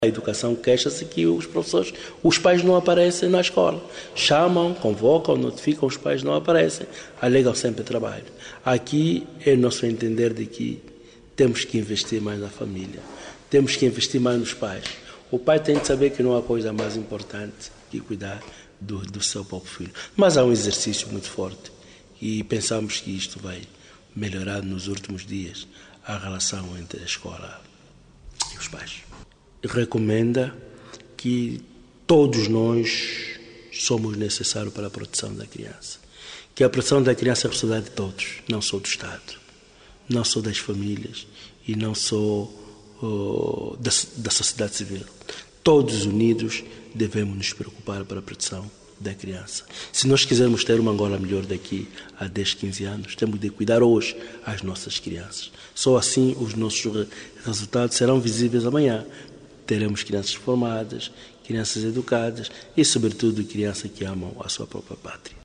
Os pais e encarregados de educação nem sempre vão a escola dos filhos sempre que convocados, segundo Paulo Kalesi, director-geral do Instituto Nacional da Criança (INAC), em declarações à RÁDIO NOVA.